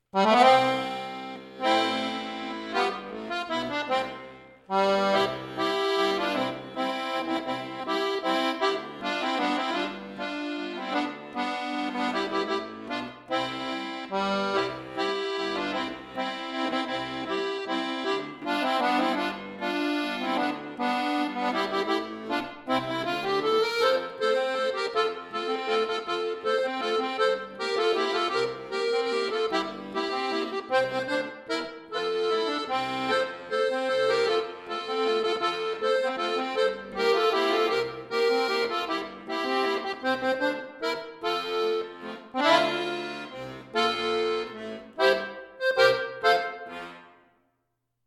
Traditioneller Alpen-Ländler
Volkslied